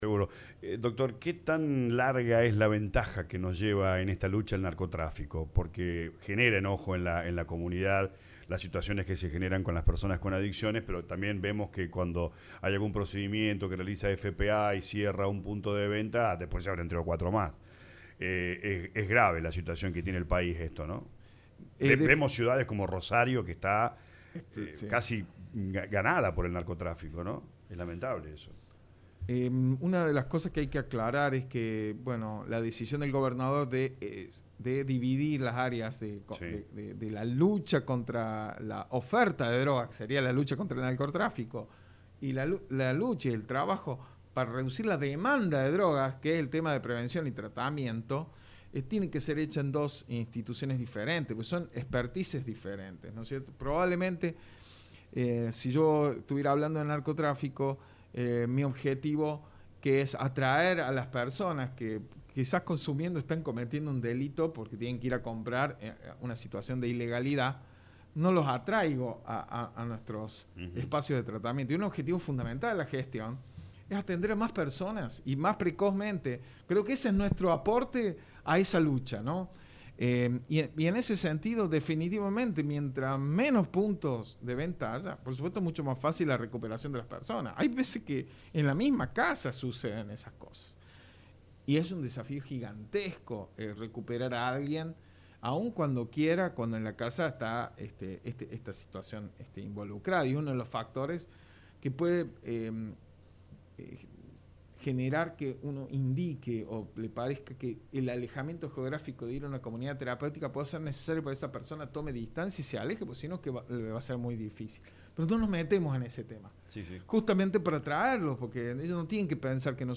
El secretario de Prevención y Asistencia de las Adicciones de la Provincia, Darío Gigena Parker, visitó LA RADIO 102.9 previo a su charla en el Teatrillo e reapertura de la RAAC Brinkmann.